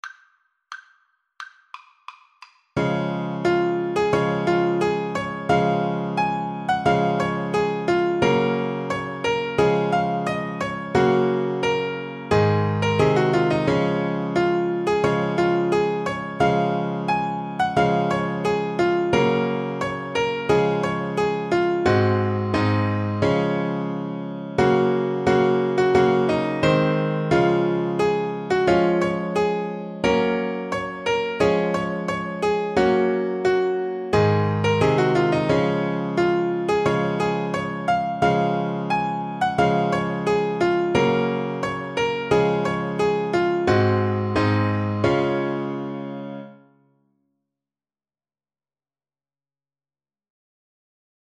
Play (or use space bar on your keyboard) Pause Music Playalong - Piano Accompaniment reset tempo print settings full screen
Db major (Sounding Pitch) Bb major (Alto Saxophone in Eb) (View more Db major Music for Saxophone )
4/4 (View more 4/4 Music)